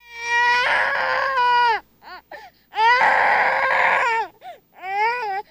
Baby Squealing and Complaining